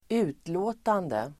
Uttal: [²'u:tlå:tande]